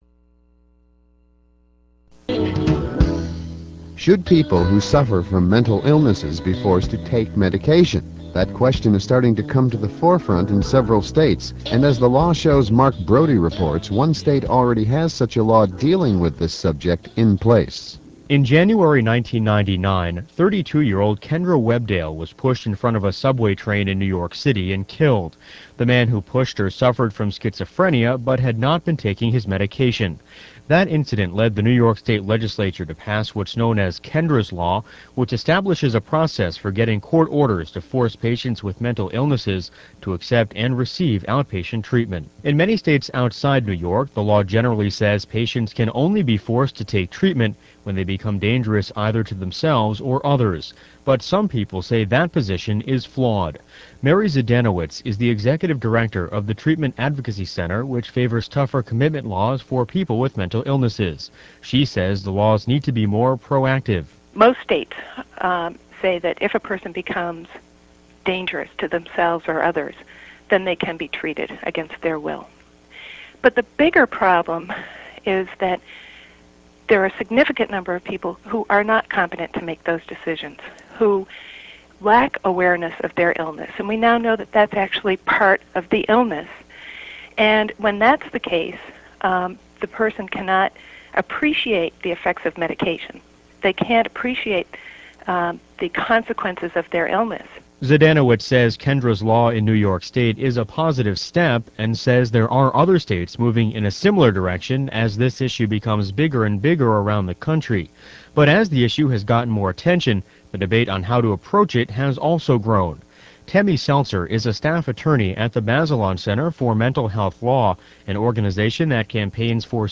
The radio segment is about seven minutes long.